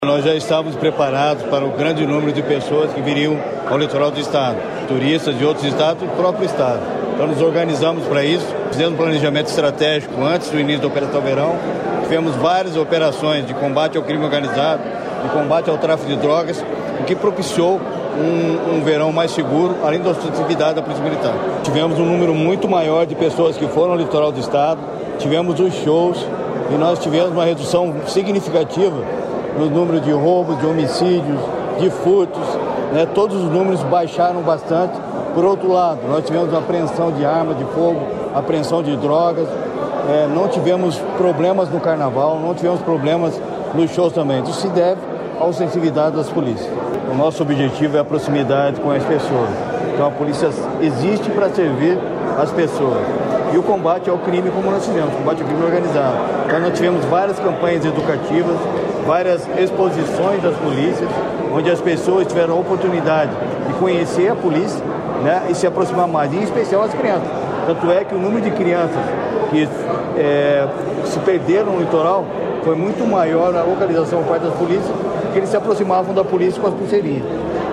Sonora do secretário da Segurança Pública, Hudson Teixeira, sobre o balanço do Verão Maior Paraná 2023/2024